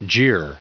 1635_jeer.ogg